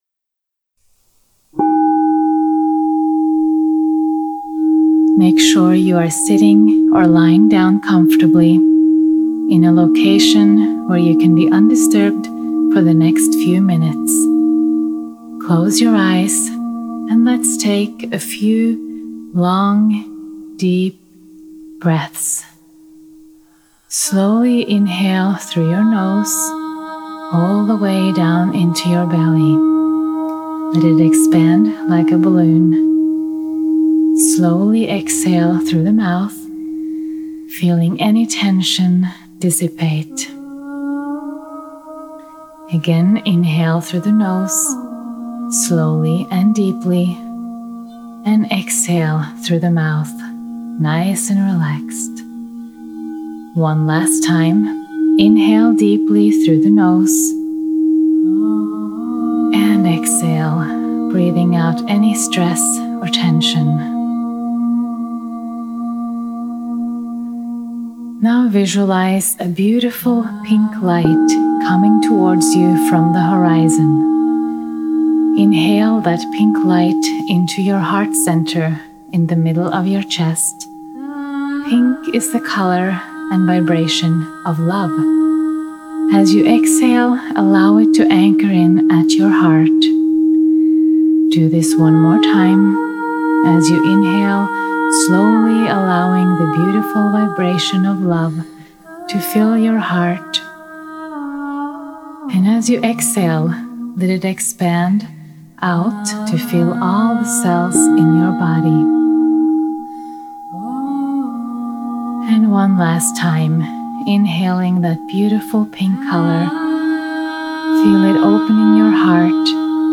They all include powerful toning and alchemical crystal bowl healing.
Gratitude meditation
gratitude-meditation.mp3